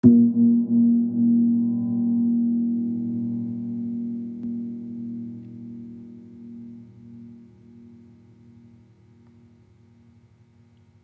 Gamelan Sound Bank
Gong-A#2-f.wav